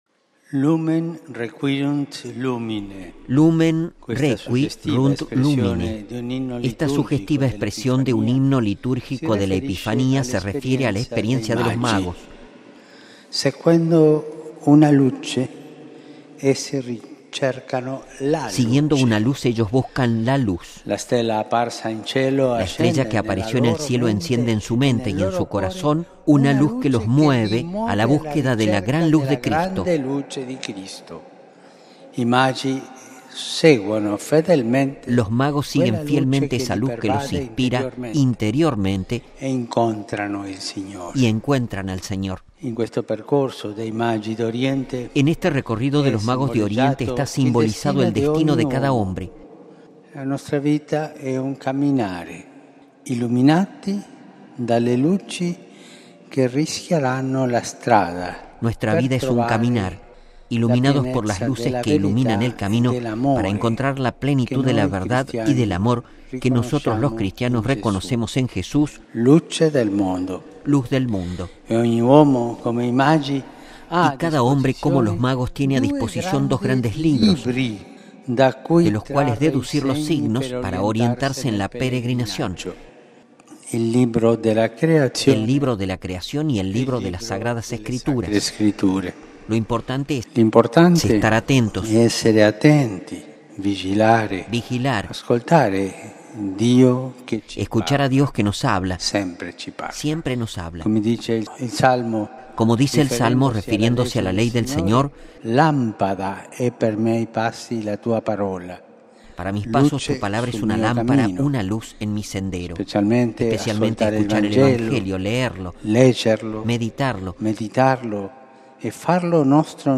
Texto completo de la homilía del Santo Padre Francisco: